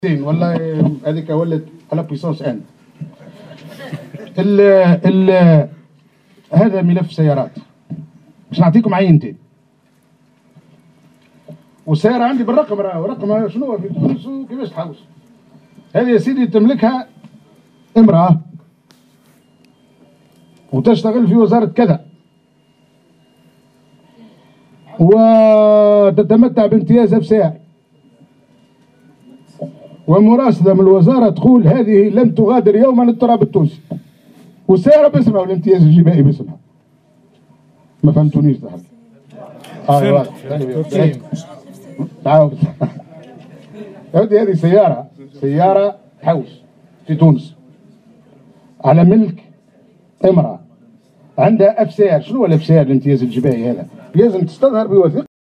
أكد وزير الوظيفة العمومية المقال عبيد البريكي، اليوم الجمعة أن موظفة بإحدى الوزارات تتمتع بامتياز "آف سي آر" الديواني الخاص بالسيارات الموردة، رغم أنها لم تغادر تونس.